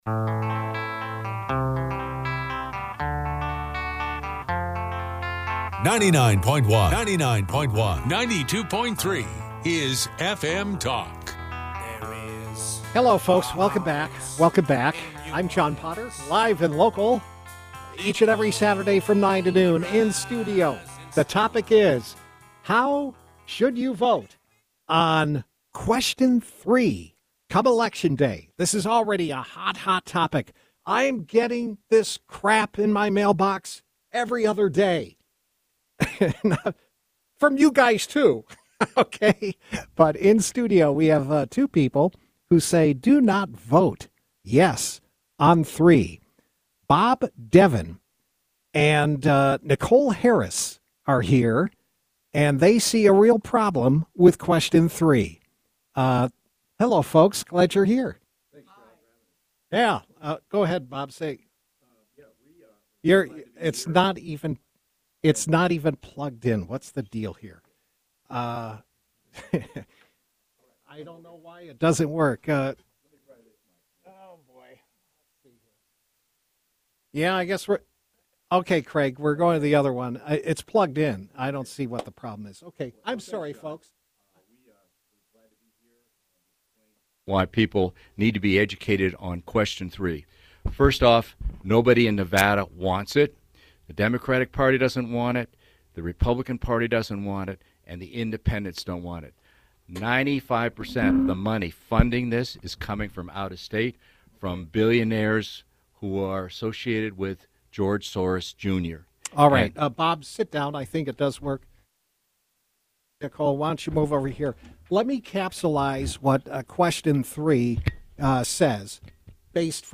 Reno Studio